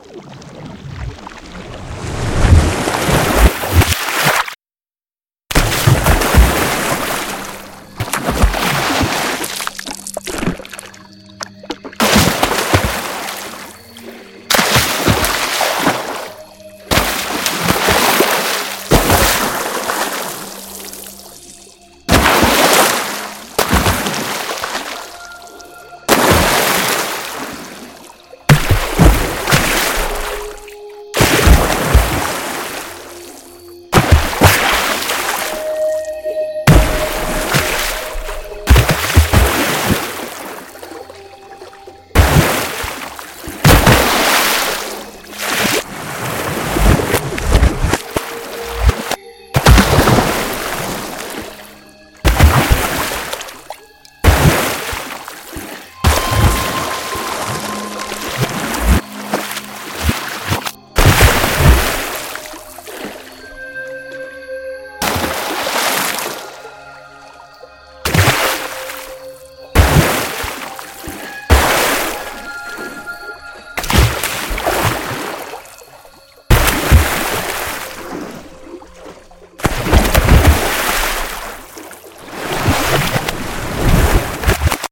音效素材-真实的水花飞溅海浪拍打流体碰撞无损音效75种
SFXTools – Water Splashes 音效素材库提供了75种精心设计的高质量水声音效，能够为任何涉及水元素的创意项目增添真实的听觉效果。
不论是商业广告、电子游戏还是影视配乐，这套素材都能提供丰富的溅水、气泡等动态声音选择。
此外，素材库还特别包含一些经过特殊处理的音效，例如反向播放、慢速运动以及水下音效等，方便用户快速匹配特定场景的需求，提升制作的灵活性和效率。
SFXTools-Water-Splashes-Preview.mp3